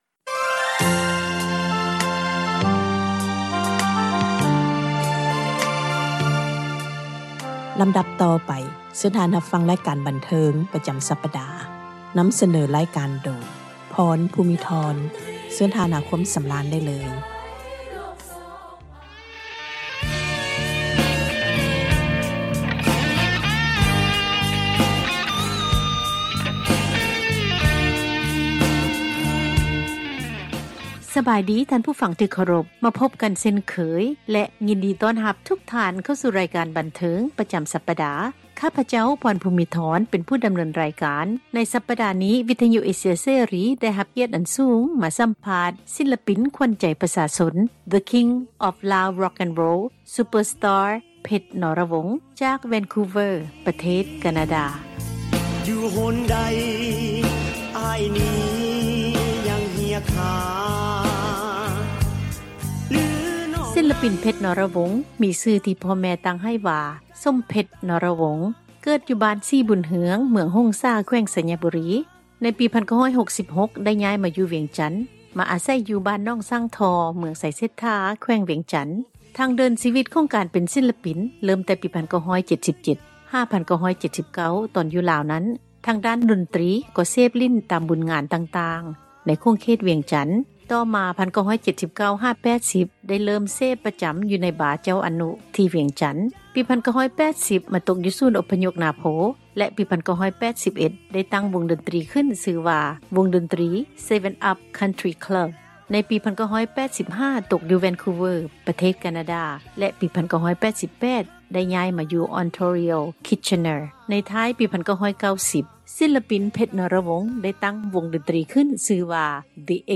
ສິລປິນ
ຣາຍການບັນເທີງ ໃນທ້າຍສັປດານີ້